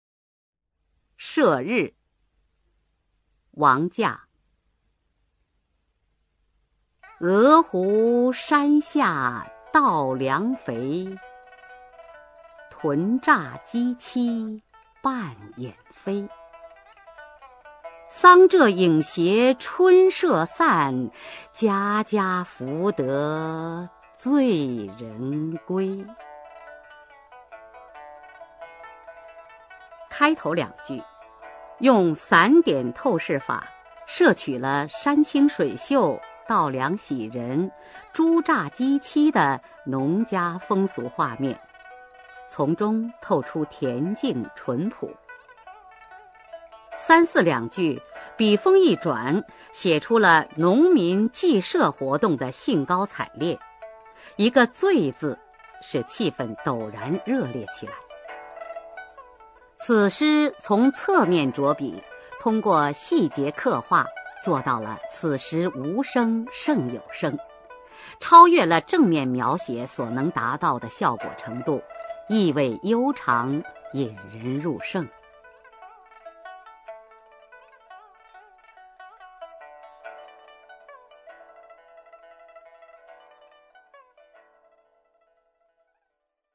[隋唐诗词诵读]王驾-社日 配乐诗朗诵